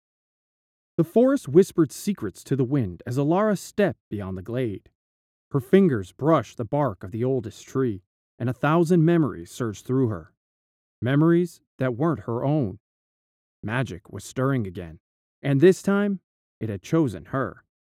Male
Yng Adult (18-29), Adult (30-50)
Audiobooks
Western Audiobook